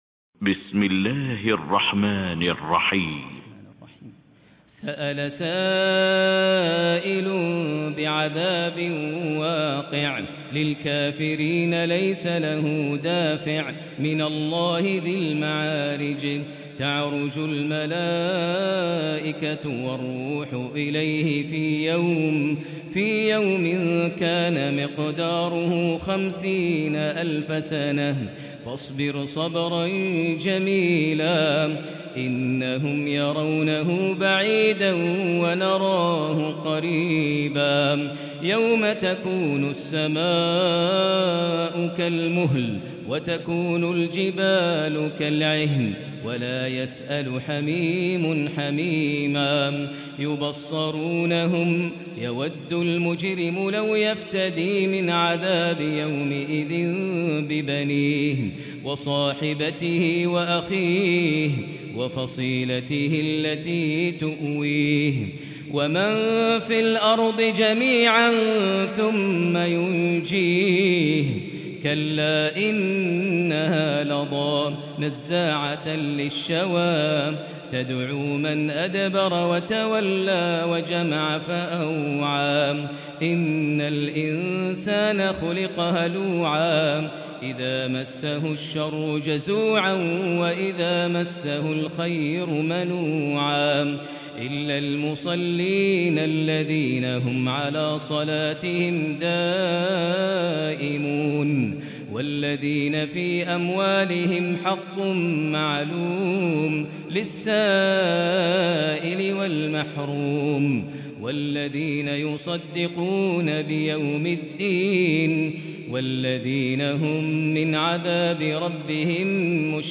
Tarawih prayer from the holy Mosque